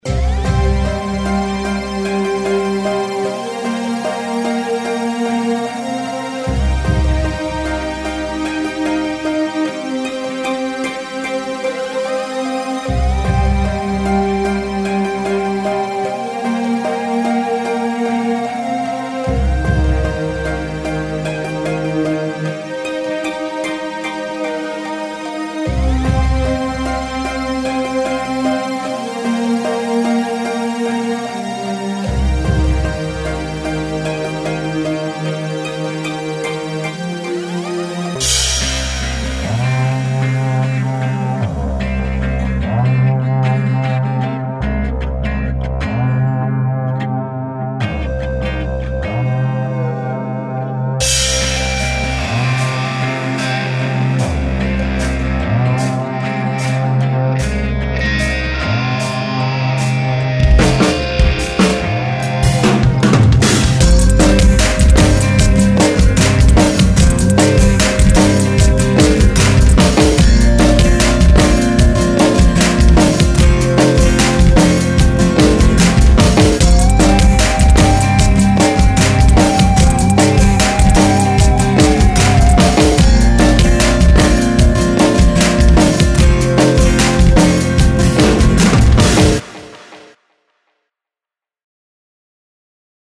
ambient/pop-rock